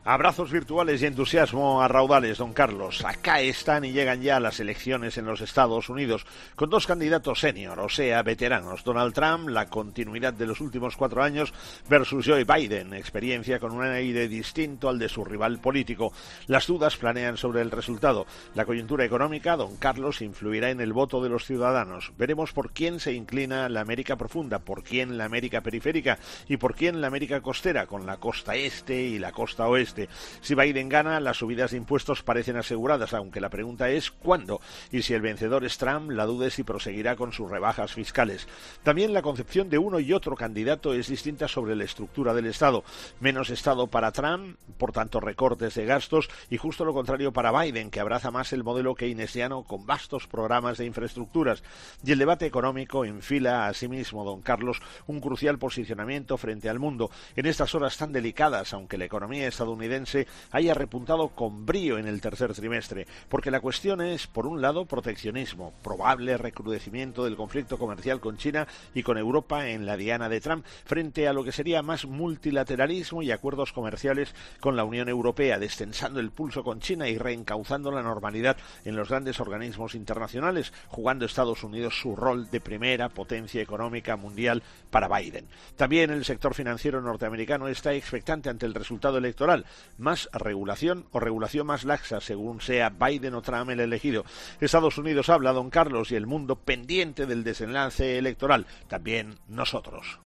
Las inminentes elecciones en los Estados Unidos, en el monólogo del profesor de este lunes 2 de noviembre de 2020